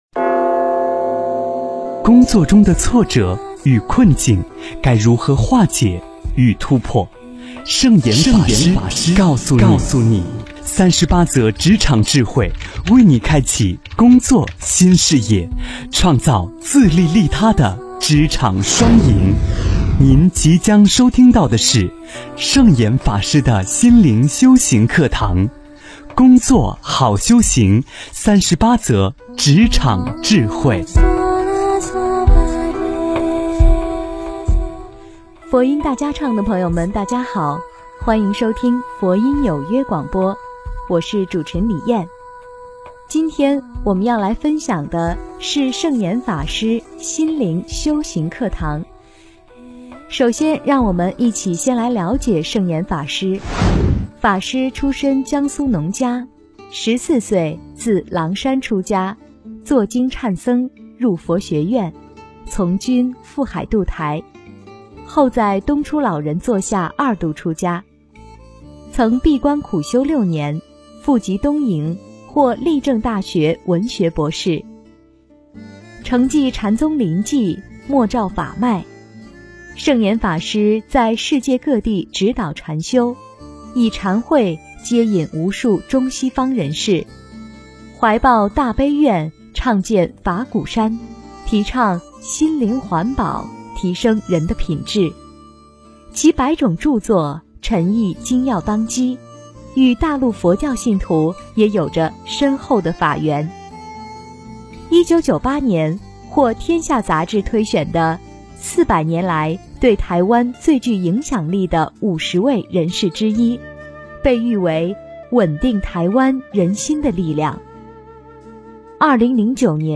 职场01奉献工作也是菩萨精神--佛音大家唱 真言 职场01奉献工作也是菩萨精神--佛音大家唱 点我： 标签: 佛音 真言 佛教音乐 返回列表 上一篇： 心经2--梦参法师 下一篇： 职场04有利他人的就是好工作--佛音大家唱 相关文章 楞严咒 楞严咒--重庆华岩寺梵呗音乐团...